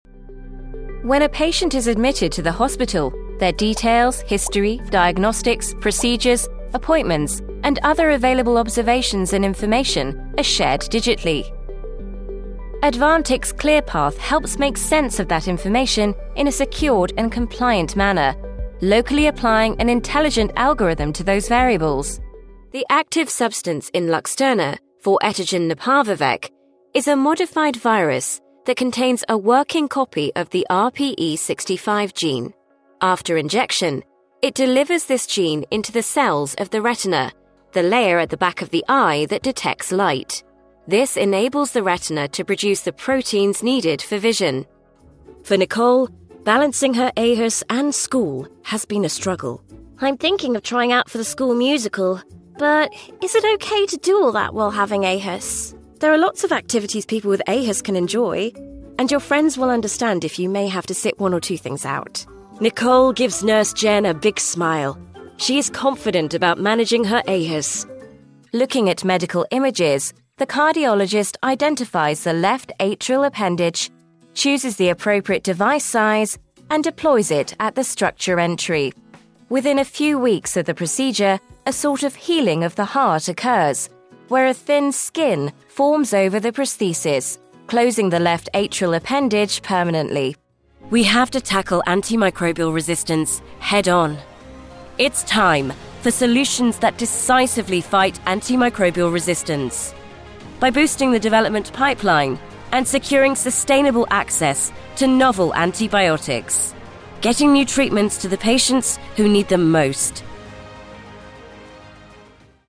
Narración médica
Micrófonos: Neumann U87, Neumann TLM 102, Sennheiser MKH 416
Cabina: Cabina vocal de doble pared a medida de Session Booth con paneles añadidos de EQ Acoustics, Auralex y Clearsonic.